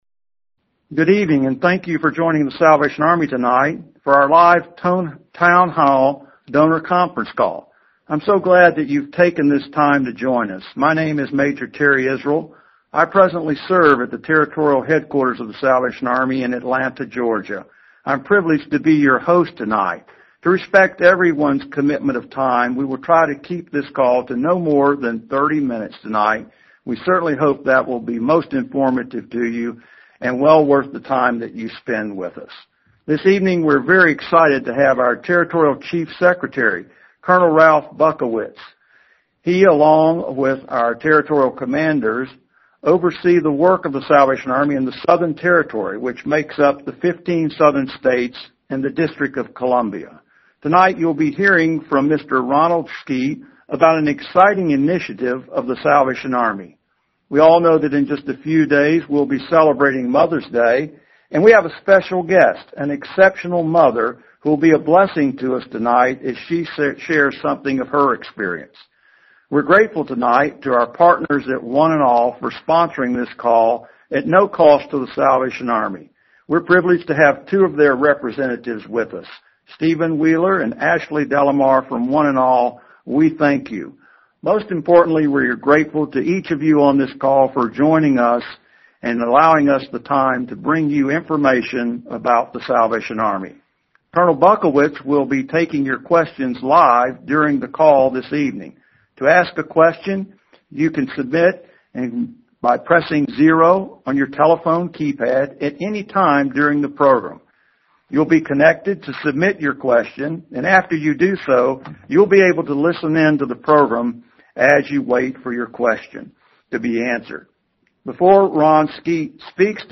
The Town Hall Meeting On Thursday